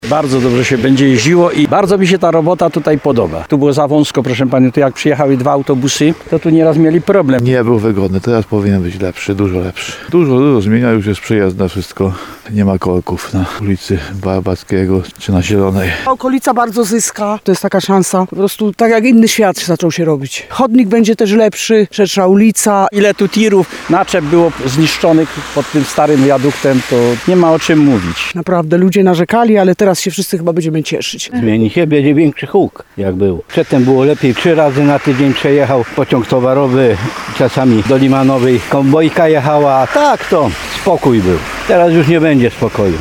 Teraz już nie będzie spokoju – mówił w rozmowie z RDN Nowy Sącz jeden z mieszkańców.
23sonda_ulwegierska.mp3